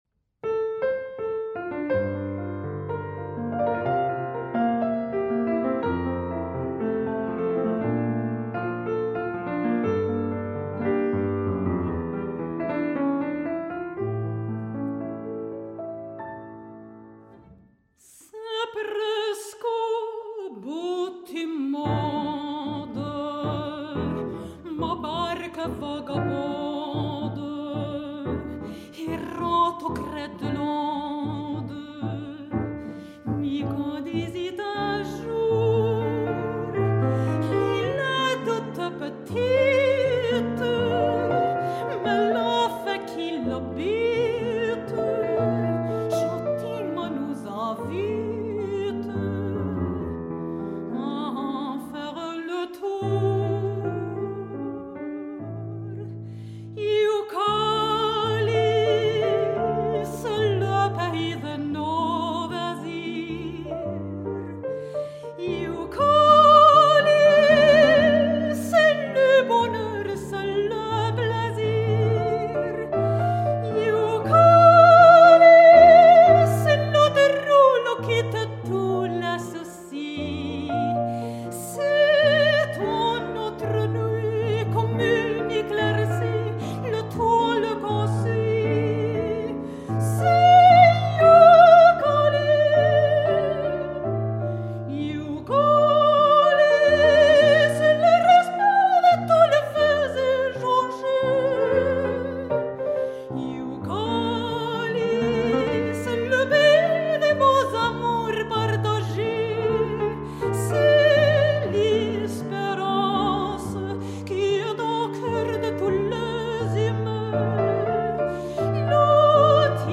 Con el repertorio de música escénica del siglo XX
recitales dramatizados